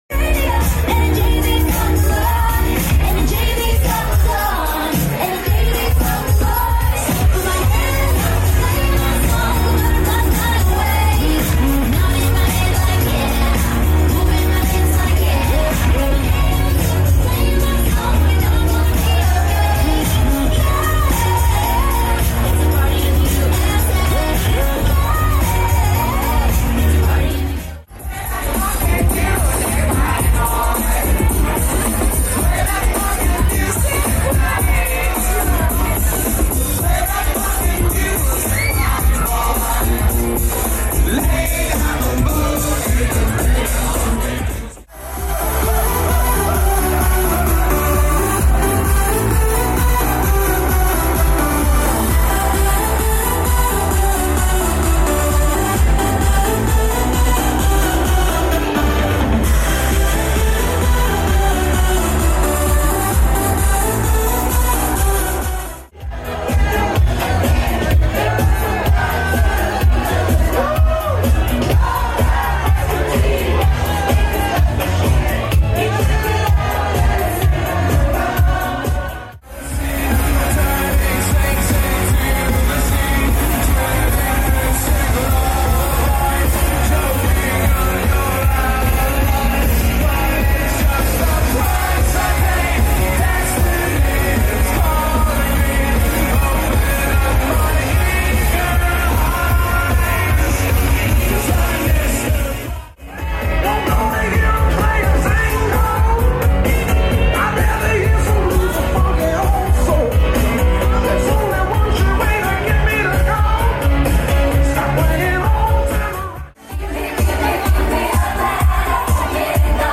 Venue: 1Hotel Toronto .